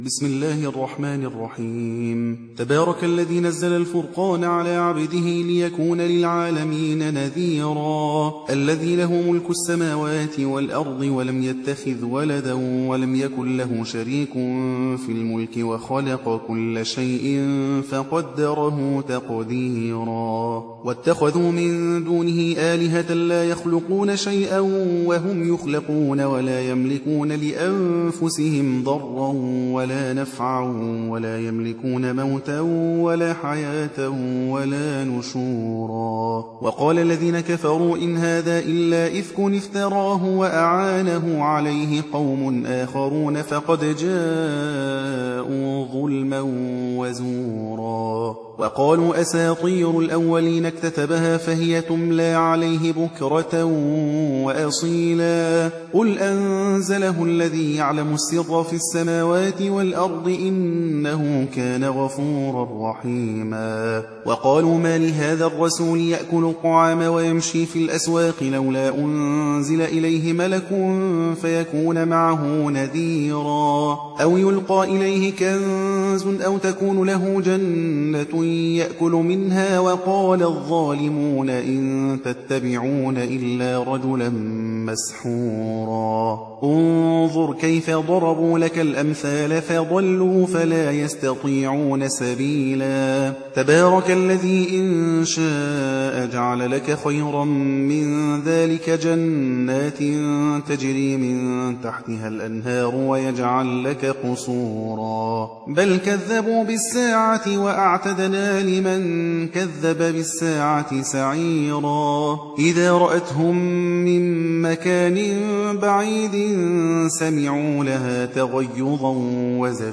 25. سورة الفرقان / القارئ